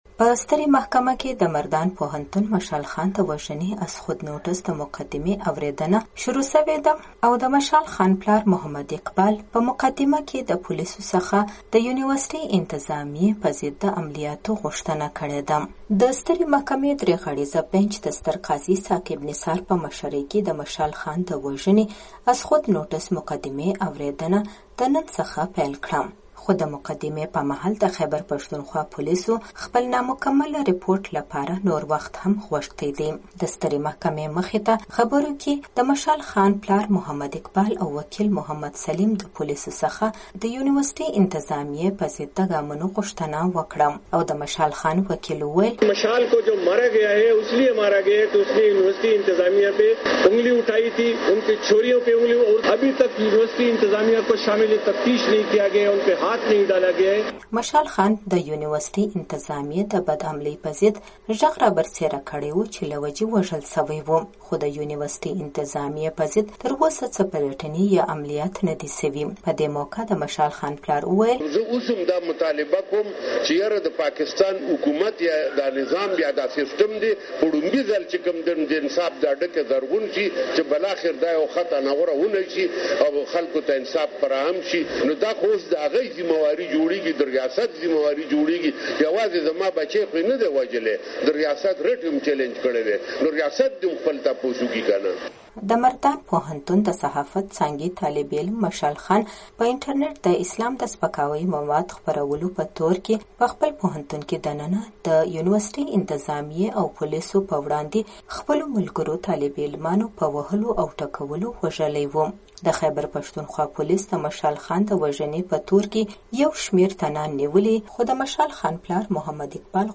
رپورټ